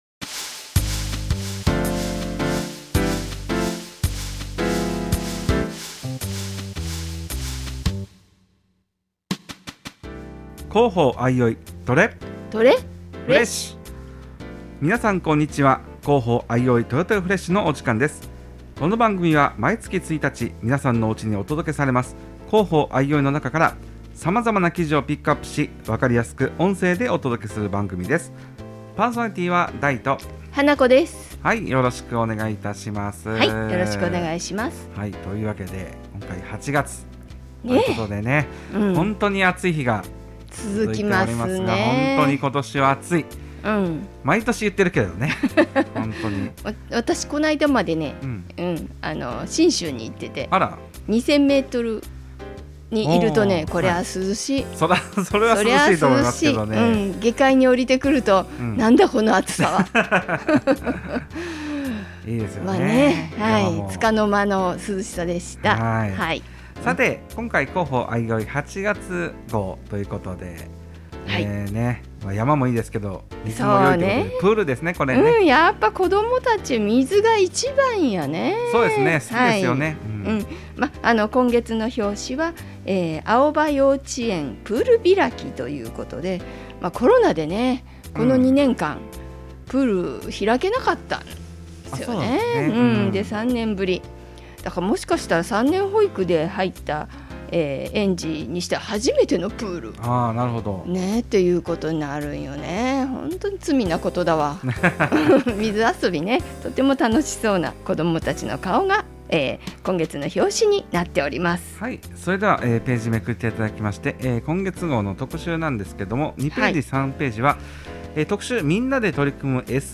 相生市内有線放送でも翌日曜日に放送中
場所：相生市生きがい交流センター